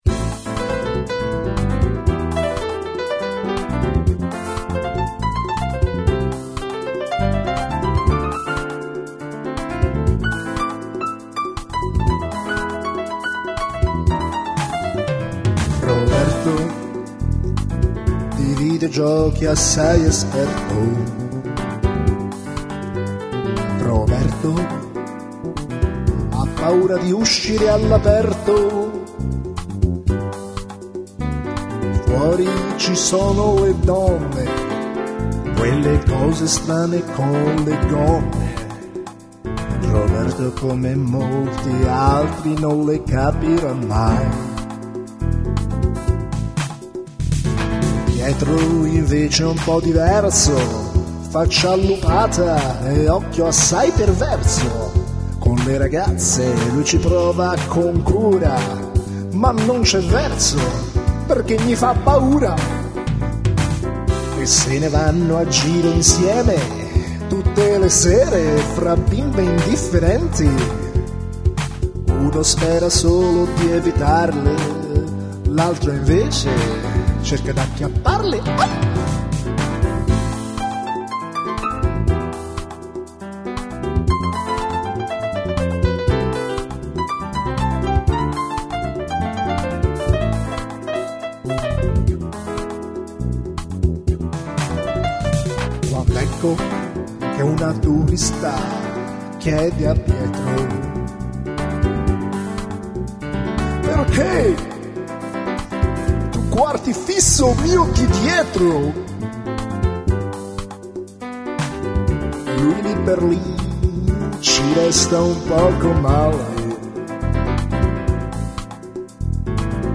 Molto spassosa e ben interpretata